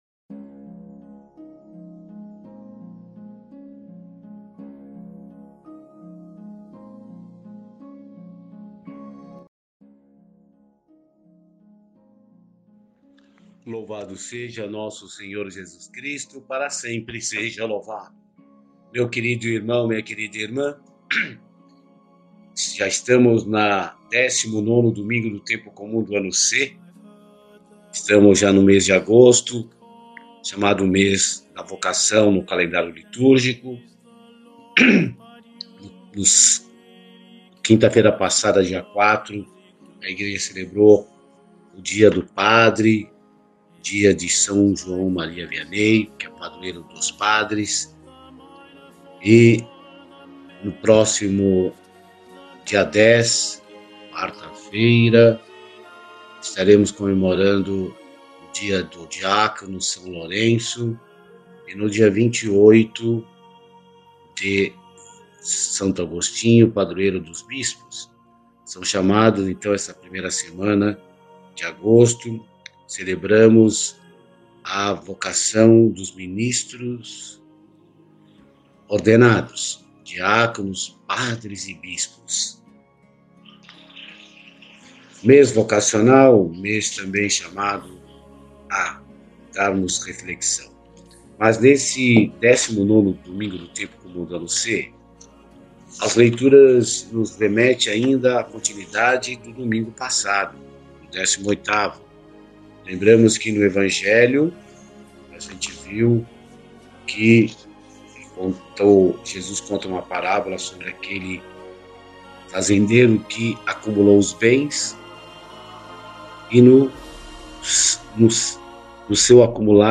Reflexão e Meditação 19 Domingo Do Tempo Comum